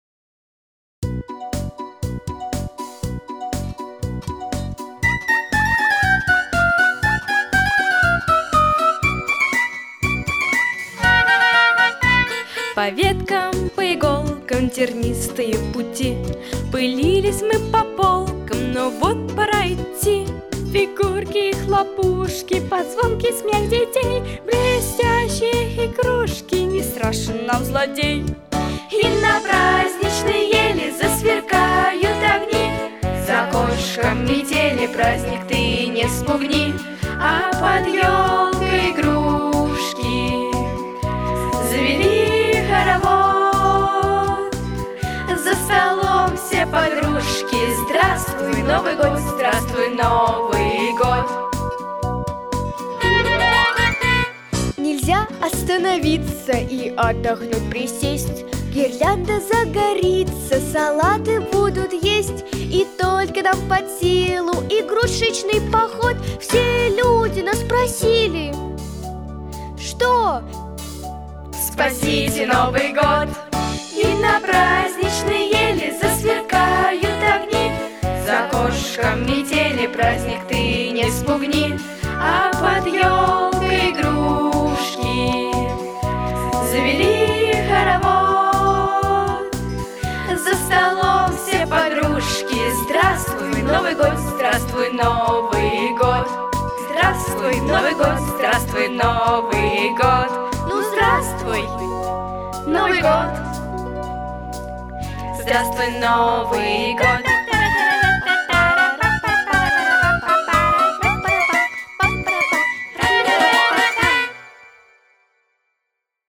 Записано в студии Easy Rider в декабре 2019 года